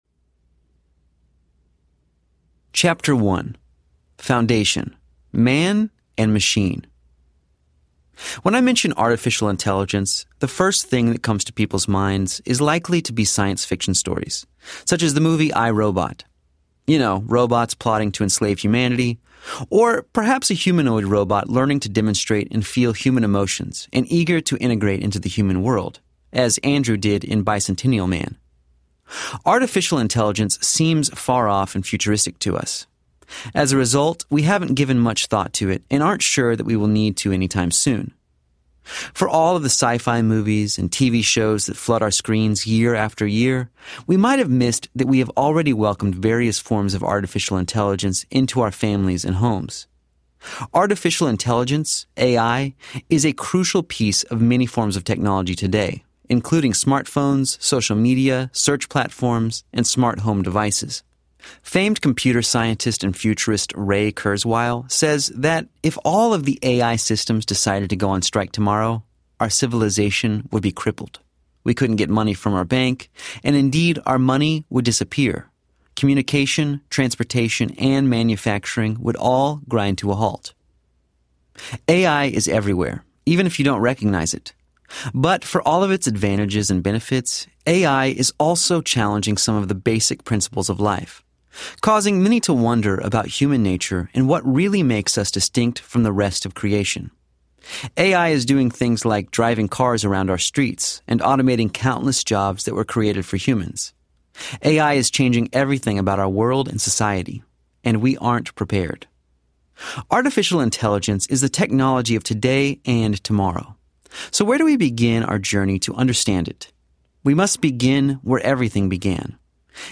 The Age of AI Audiobook
Narrator
4.8 Hrs. – Unabridged